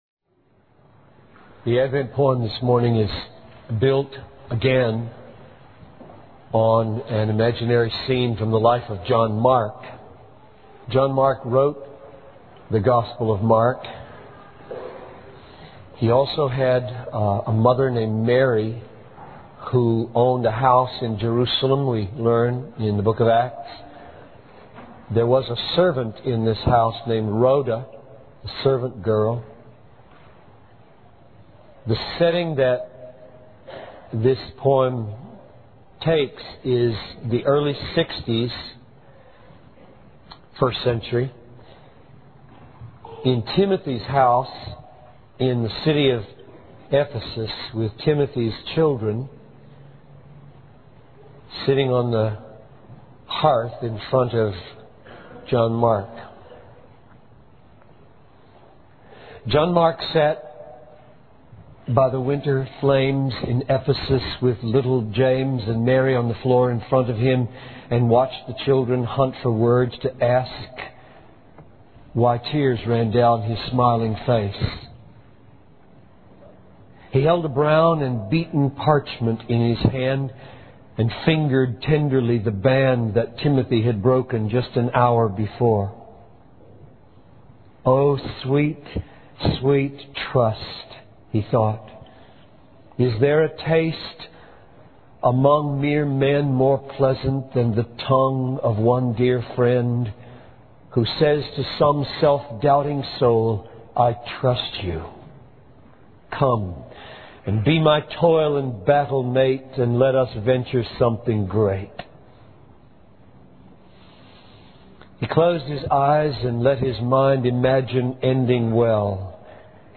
The sermon emphasizes the importance of trust and overcoming fear in order to fulfill God's purpose.